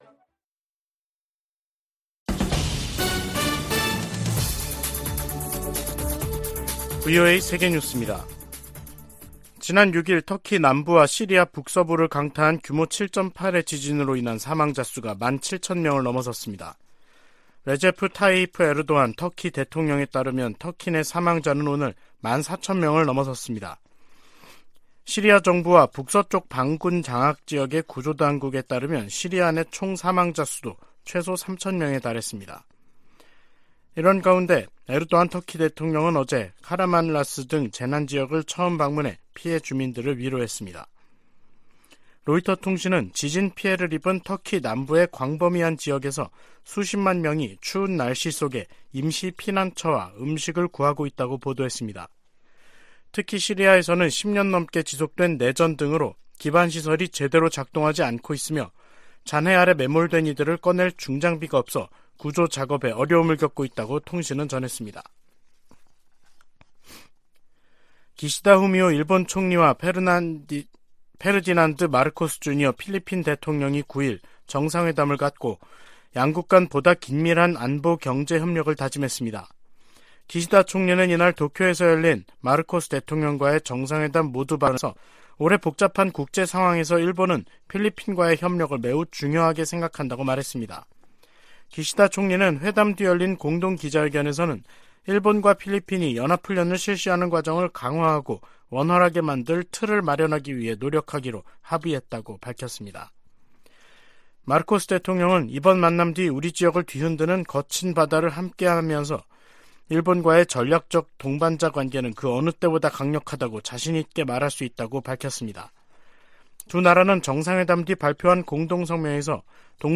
VOA 한국어 간판 뉴스 프로그램 '뉴스 투데이', 2023년 2월 9일 3부 방송입니다. 북한 건군절 기념 열병식에서 고체연료 대륙간탄도 미사일, ICBM으로 추정되는 신형 무기가 등장했습니다. 북한의 핵・미사일 관련 조직으로 추정되는 미사일총국이 공개된 데 대해 미국 정부는 북한 미사일 개발을 억지하겠다는 의지를 확인했습니다.